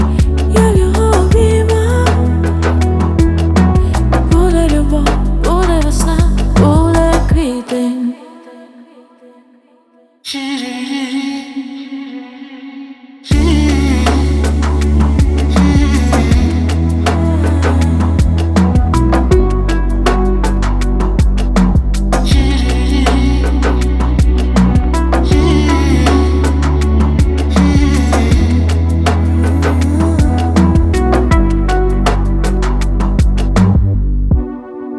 Жанр: Альтернатива / Украинские